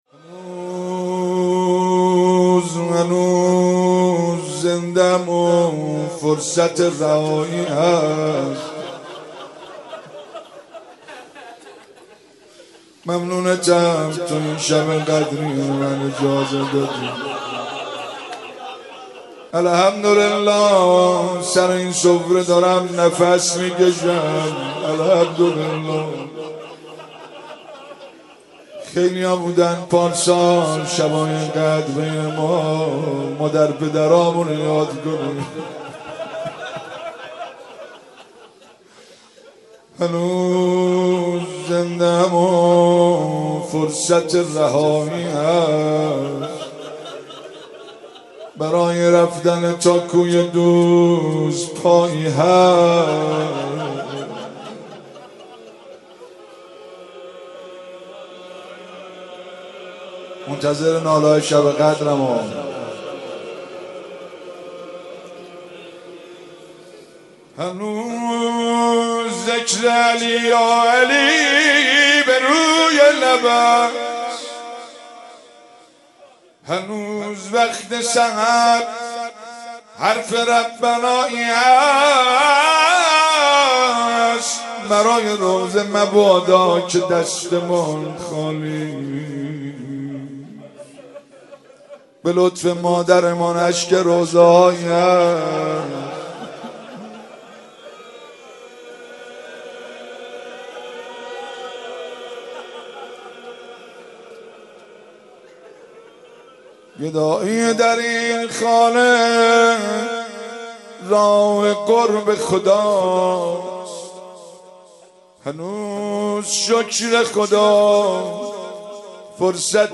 مناسبت : شب نوزدهم رمضان - شب قدر اول
قالب : مناجات